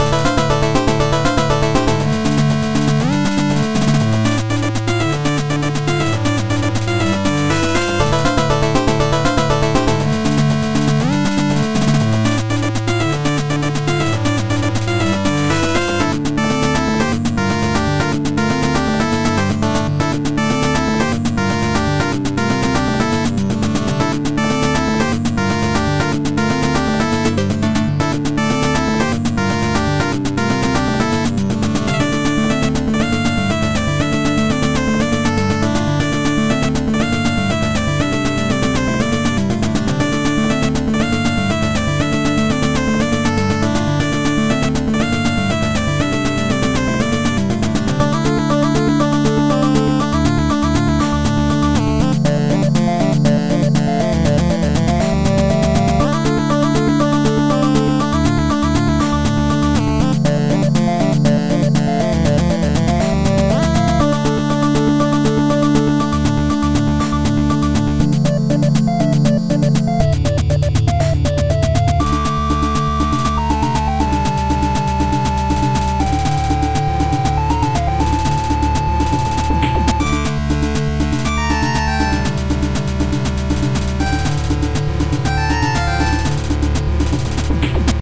A remake of my own previous chiptune song, Suez Crisis.